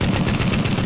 DISTGUN.mp3